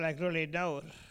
Fonds Arexcpo en Vendée
Catégorie Locution